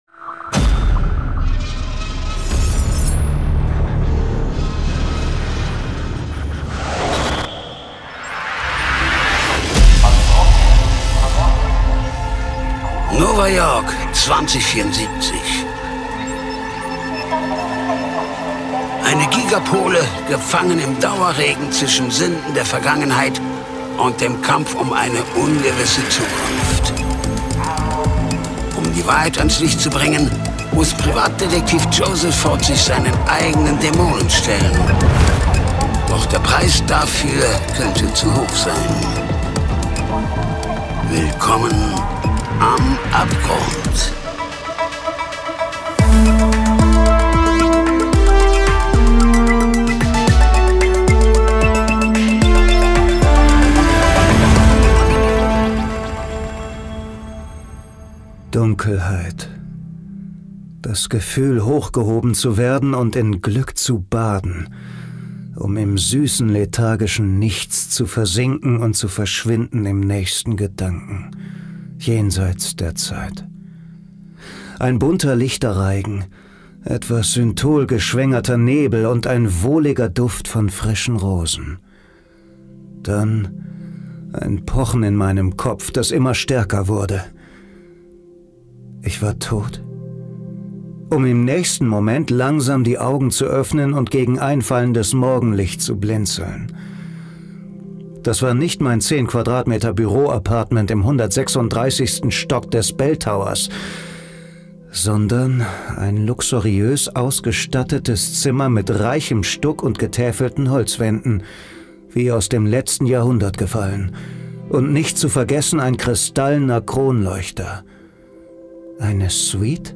Die SciFi-Noir-Krimi-Hörspielserie Podcast